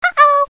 Звуки аськи ICQ
Одиночный звук сообщения аськи ооу